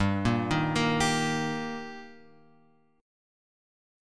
Gm#5 chord {3 1 1 3 x 3} chord